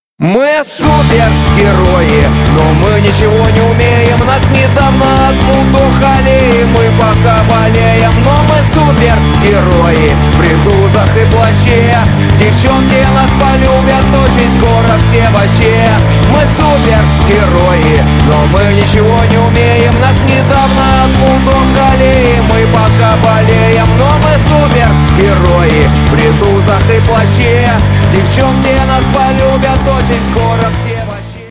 • Качество: 128, Stereo
веселые
русский рок
Веселая песенка от очень веселой группы